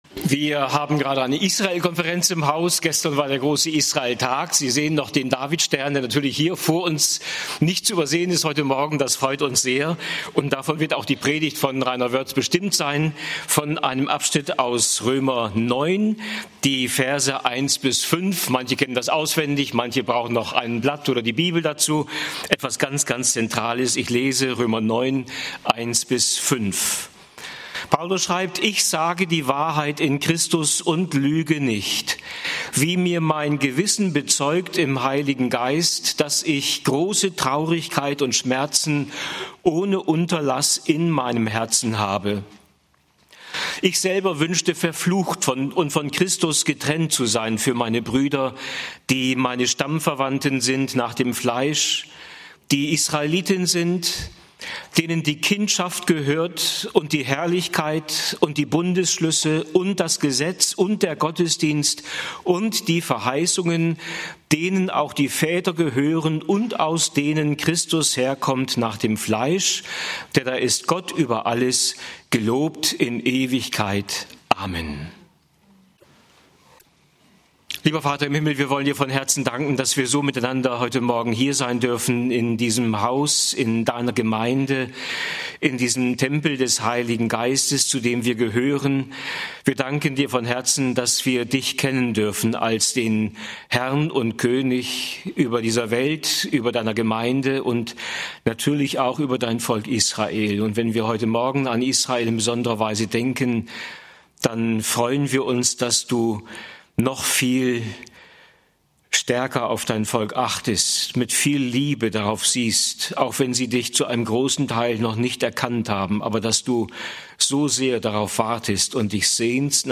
In Paulus schlägt Gottes Herz für sein Volk (Rö. 9, 1-5) - Gottesdienst